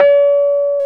KEY RHODS 0K.wav